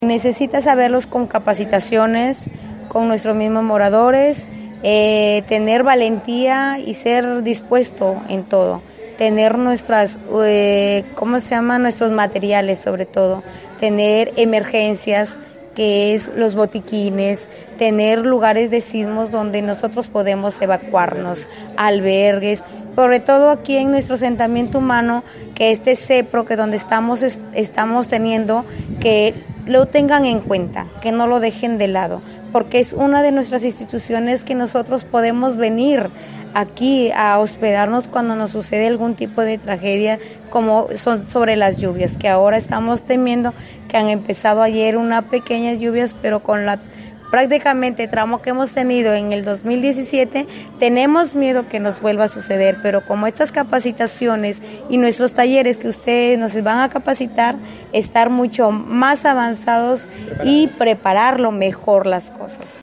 Allí tiene lugar un grupo focal en el que participan representantes de la sociedad civil, especialmente mujeres de este asentamiento, coordinados por COOPI y CARE Perú en el marco del proyecto “Vamos Piuranos”, financiado por la Unión Europea y desarrollado en alianza con instituciones locales de Piura, INTELTA-Radio Cutivalú y las mencionadas ONGs.